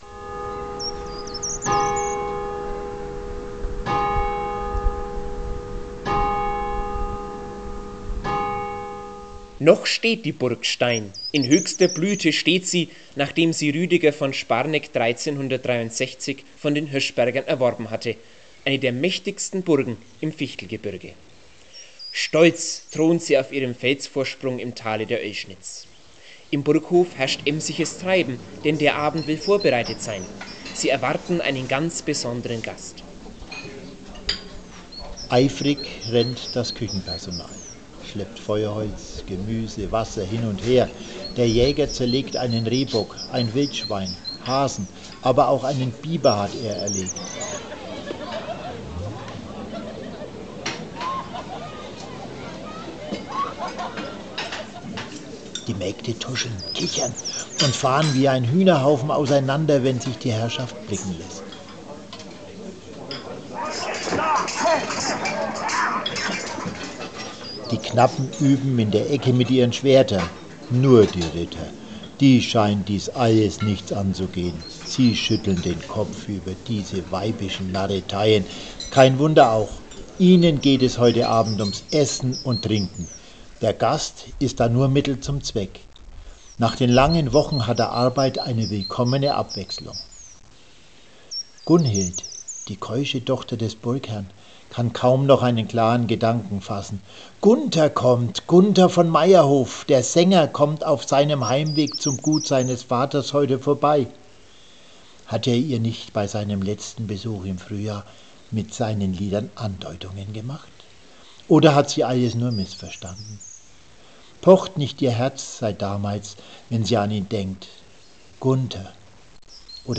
Hörspiel: Mittelalterliches Leben auf Stein